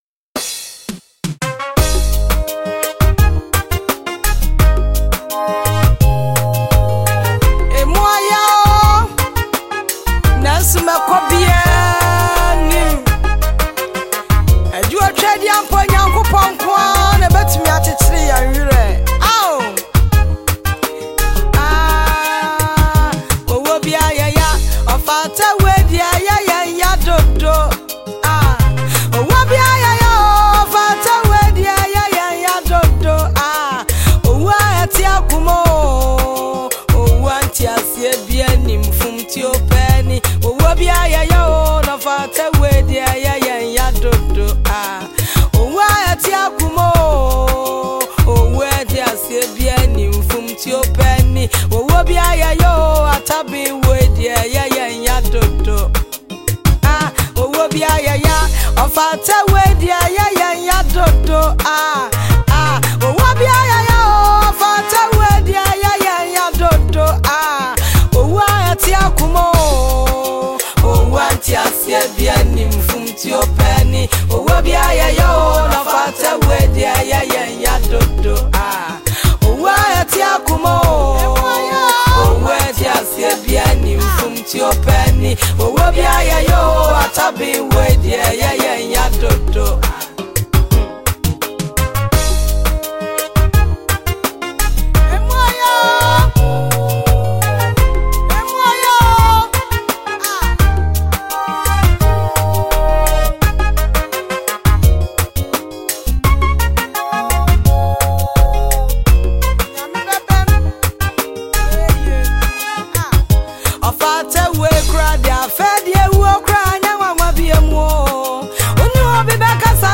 Ghanaian female Gospel singer